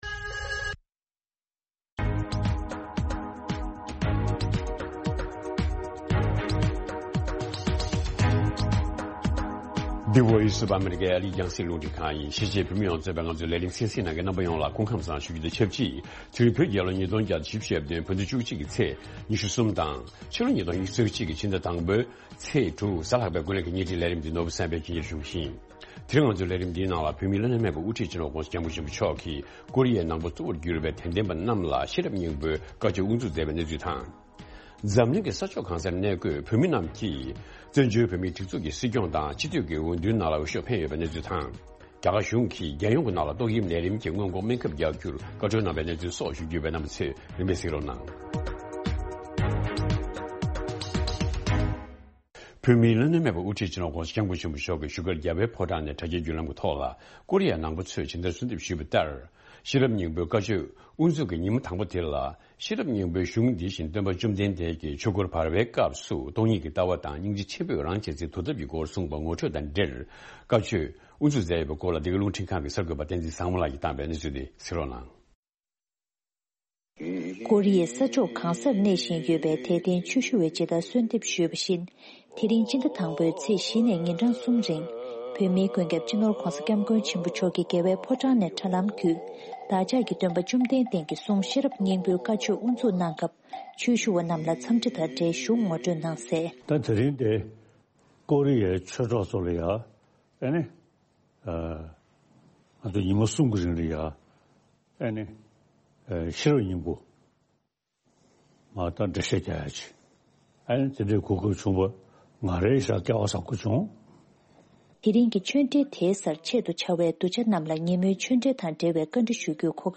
ཨ་རིའི་རླུང་འཕྲིན་ཁང་གི་བཞུགས་སྒར་དྷ་རམ་ས་ལ་དང་། རྒྱ་གར་ལྷོ་ཕྱོགས། རྒྱལ་ས་ལྡི་ལི་བཅས་སུ་ཡོད་པའི་གསར་འགོད་པ་གསུམ་དང་བགྲོ་གླེང་ཞུས་